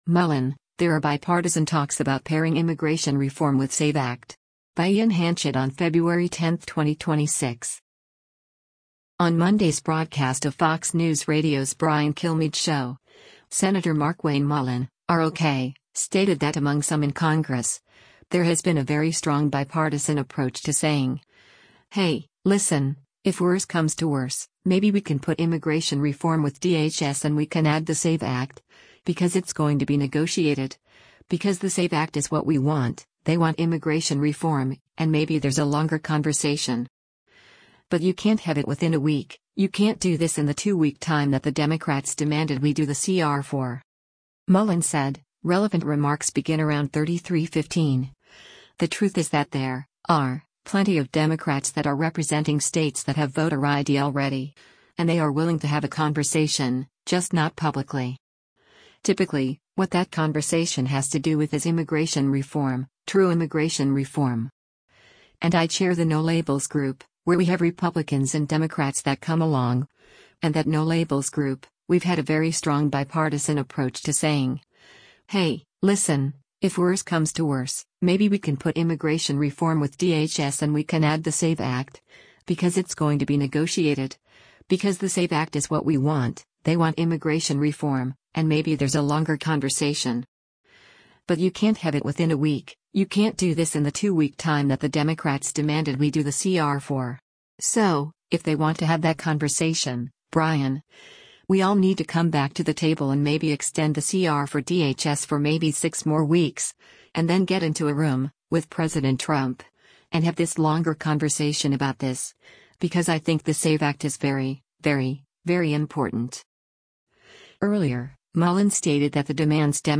On Monday’s broadcast of Fox News Radio’s “Brian Kilmeade Show,” Sen. Markwayne Mullin (R-OK) stated that among some in Congress, there has been “a very strong bipartisan approach to saying, hey, listen, if worse comes to worse, maybe we can put immigration reform with DHS and we can add the SAVE Act, because it’s going to be negotiated, because the SAVE Act is what we want, they want immigration reform, and maybe there’s a longer conversation.